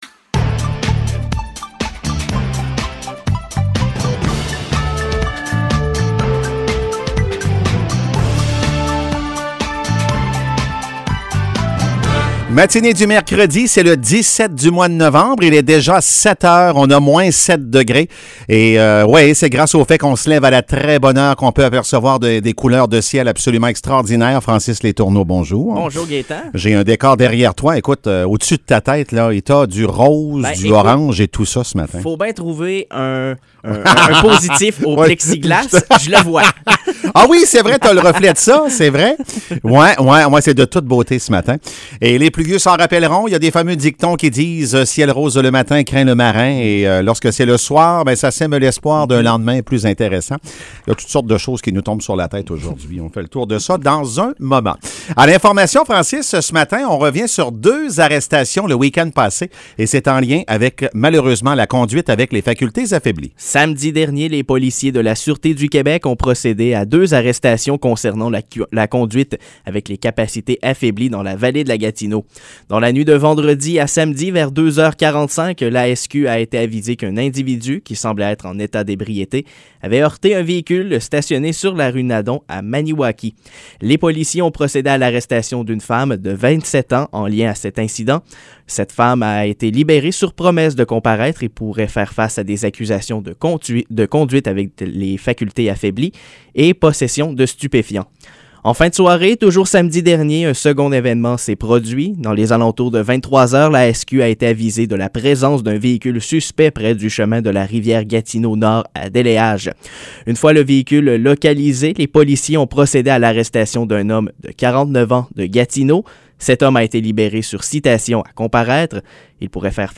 Nouvelles locales - 17 novembre 2021 - 7 h